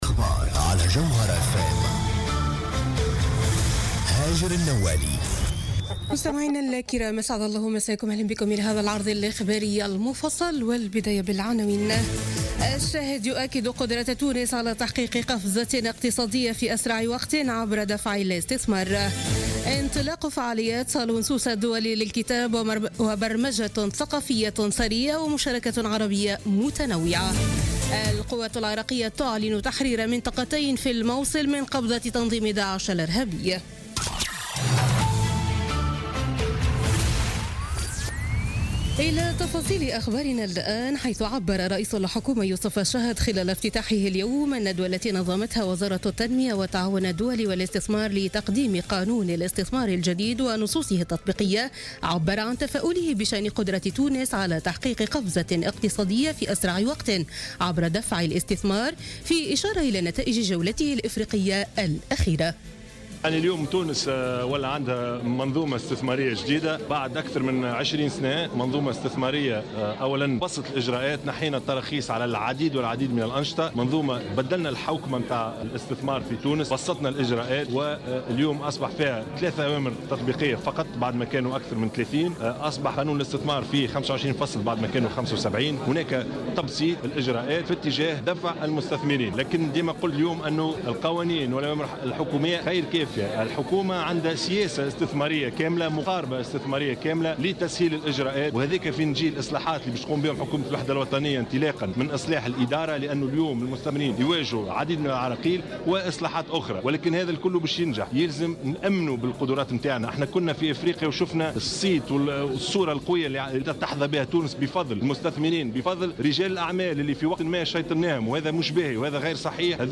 نشرة أخبار السابعة مساء ليوم السبت 8 أفريل 2017